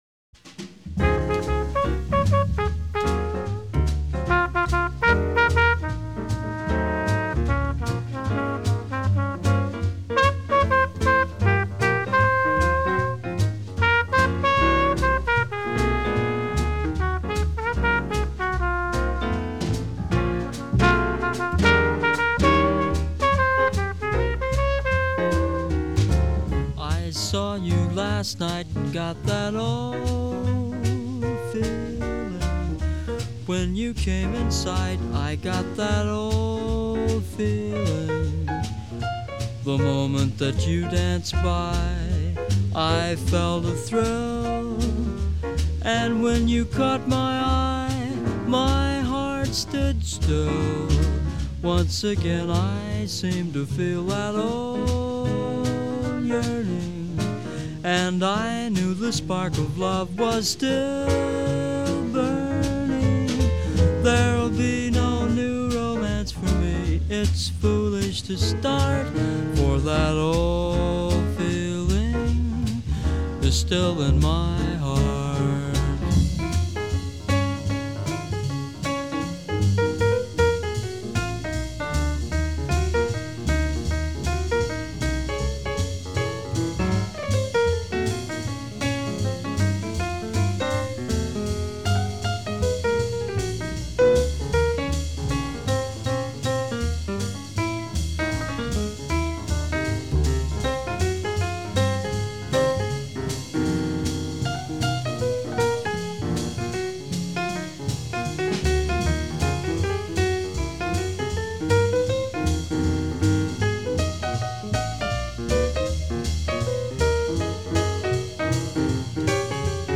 If you’re a jazz novice